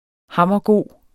Udtale [ ˈhɑmˀʌˈ- ]